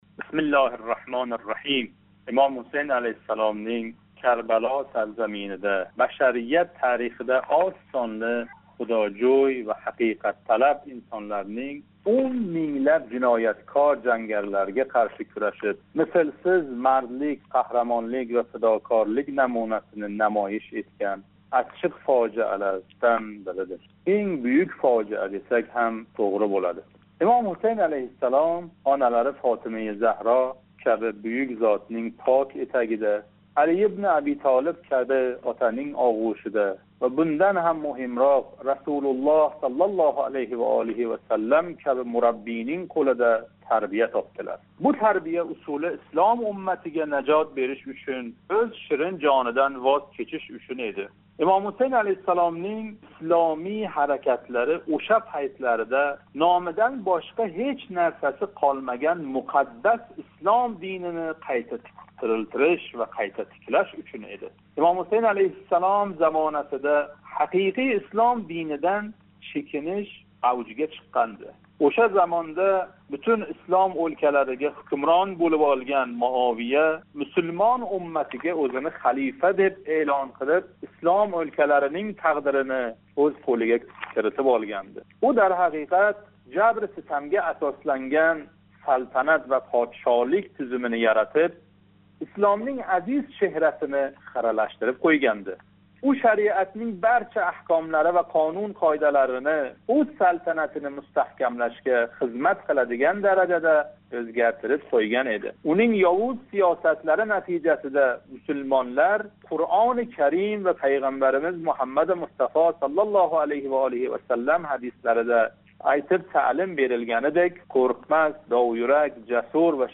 Диний масалаларга оид таҳлилчи